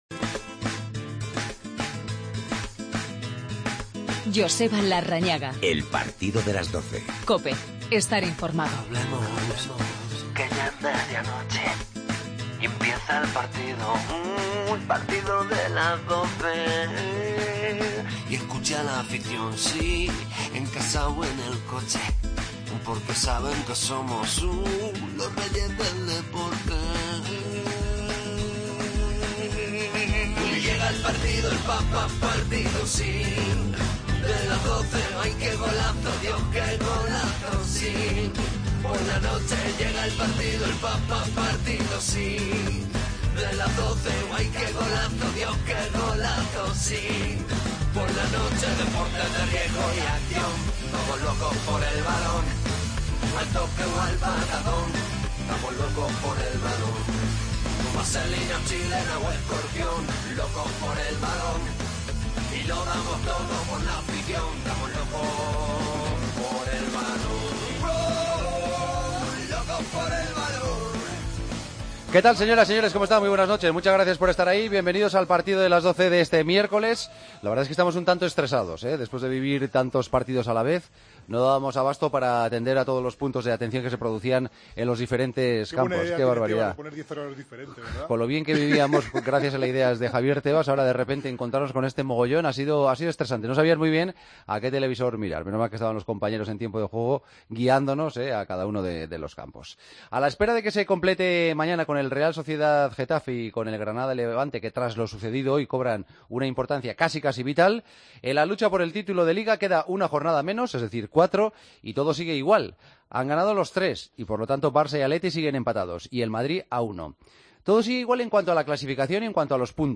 Todo sigue igual en la Liga a falta de cuatro jornadas después de las victorias del Barcelona, Atlético y Real Madrid. Cristiano Ronaldo se retiró con molestias musculares. Entrevistas a Lucas y escuchamos a Rakitic, Zidane y Danilo.